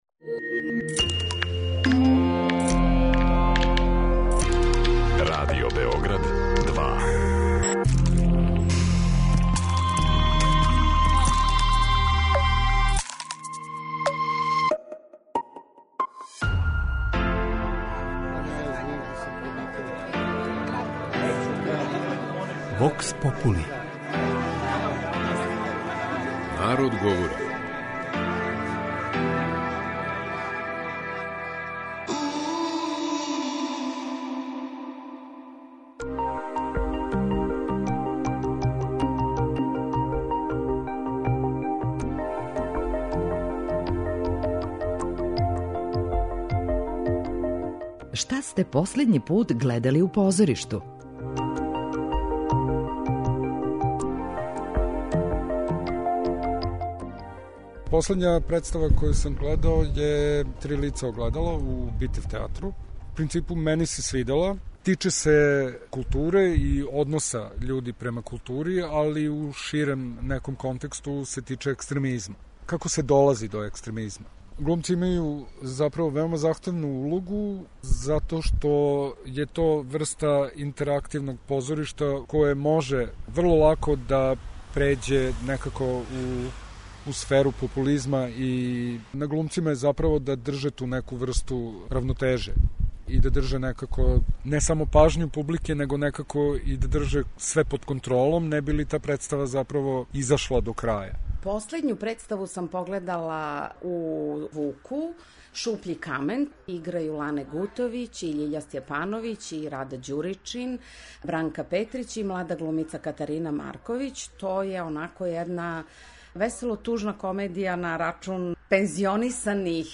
кроз кратке монологе, анкете и говорне сегменте